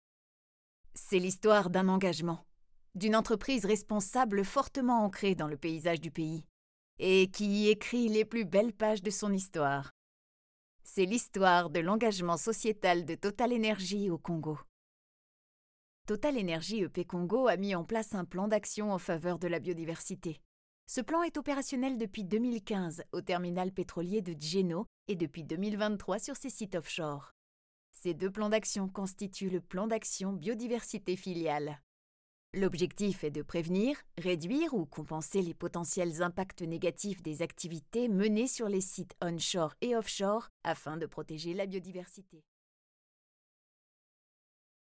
Jeune, Enjouée, Commerciale, Naturelle, Polyvalente
E-learning